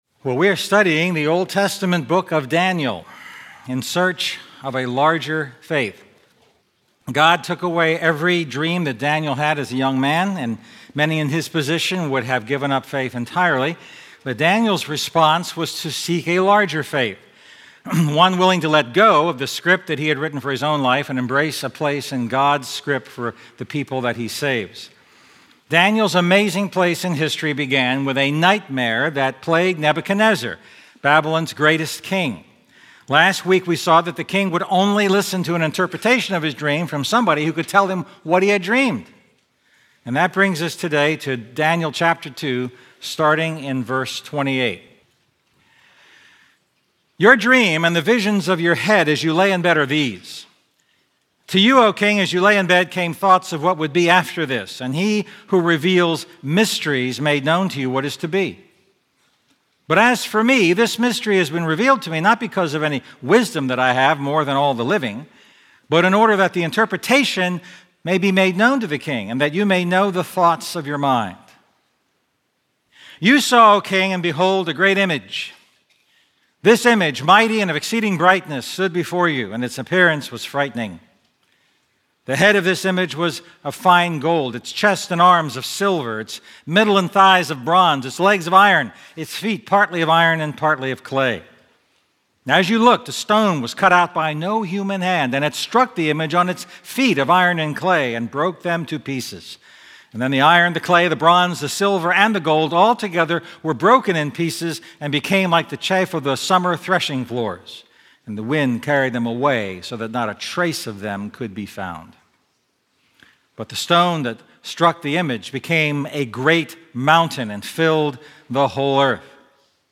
A message from the series "A Larger Faith."